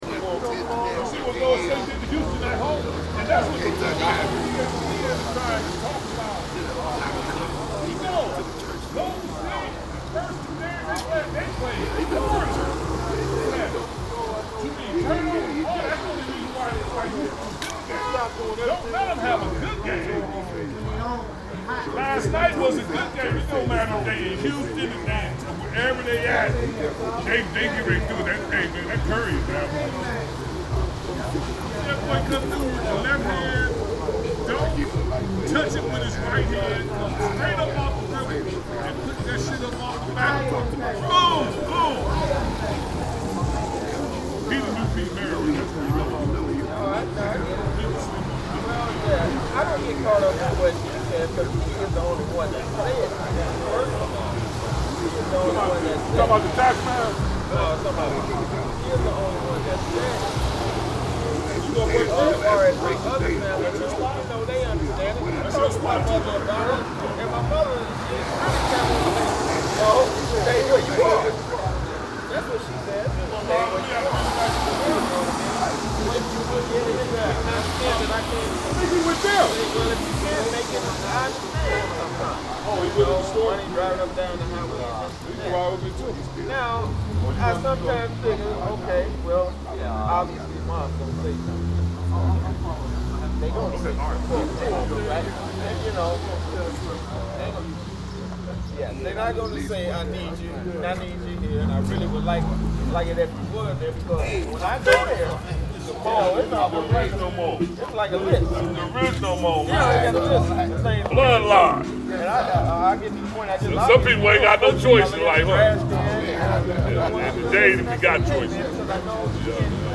Half-heard conversations in Minneapolis
A snapshot of the intersection at 35th St and Chicago Ave, Minneapolis, MN, on a Sunday afternoon after a church service - listen out for fascinating snippets of half-heard conversations.